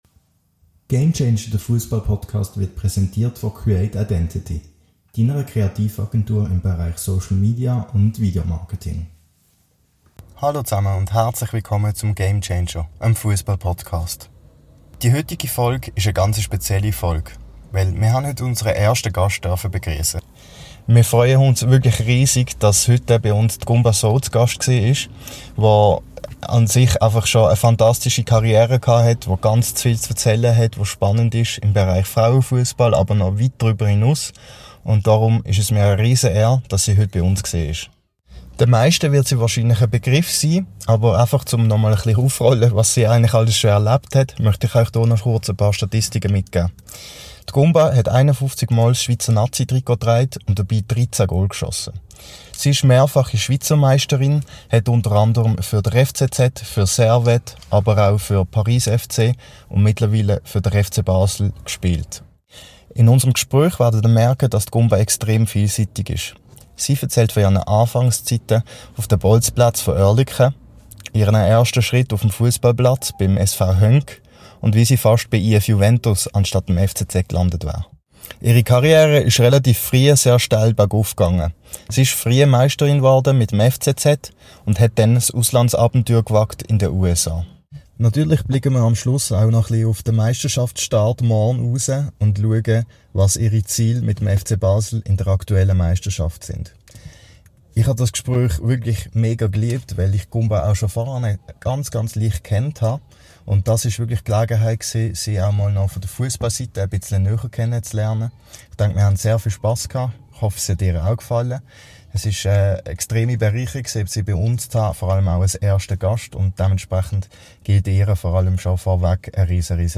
Im Gespräch mit ihr sprechen wir über ihre erfolgreiche Karriere und blicken auf ihre Anfänge in Oerlikon zurück.